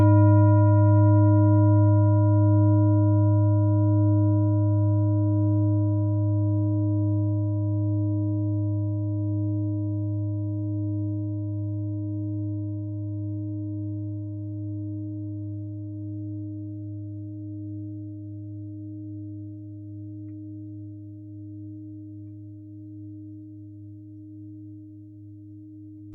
Klangschalen-Typ: Tibet
Klangschale Nr.2
Gewicht = 2140g
Durchmesser = 27,9cm
(Aufgenommen mit dem Filzklöppel/Gummischlegel)
klangschale-set-6-2.wav